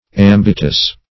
Search Result for " ambitus" : The Collaborative International Dictionary of English v.0.48: Ambitus \Am"bi*tus\ ([a^]m"b[i^]*t[u^]s), n. [L. See Ambit , Ambition .] 1.